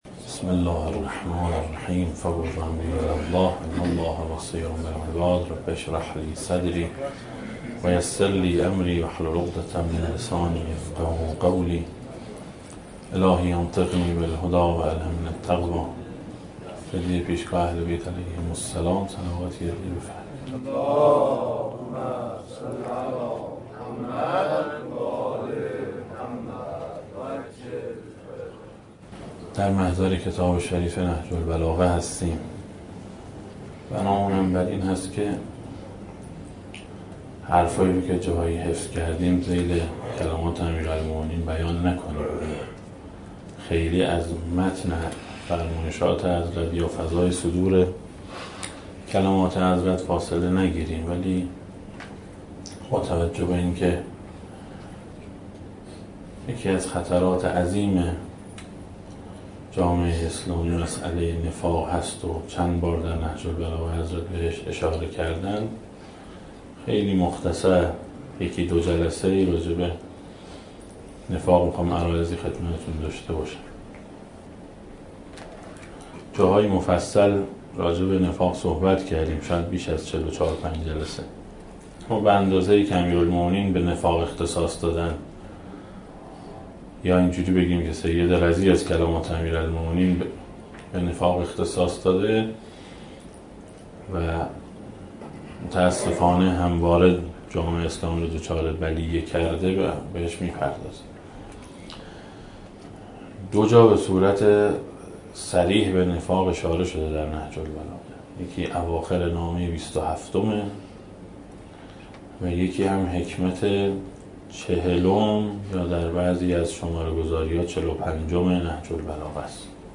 دسته: امیرالمومنین علیه السلام, درآمدی بر نهج البلاغه, سخنرانی ها